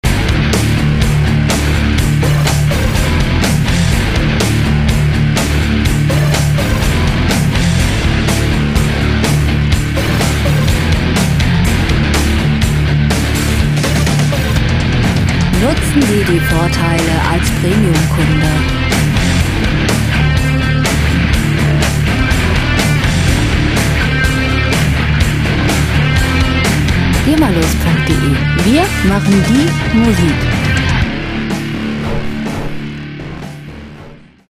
Rockmusik - Harte Männer
Musikstil: Hard Rock
Tempo: 124 bpm
Tonart: D-Moll
Charakter: stumpf, aufmüpfig
Instrumentierung: verzerrte E-Gitarre, E-Bass, Drums, FX